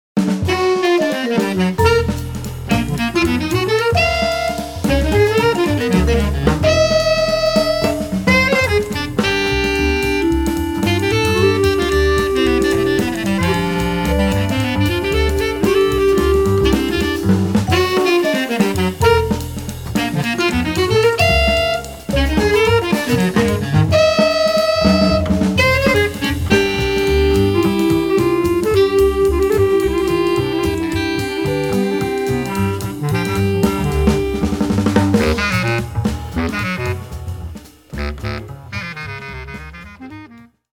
Bass Clarinet
Vibraphone
Double-Bass
Drums
at Skyline Production, South Orange, NJ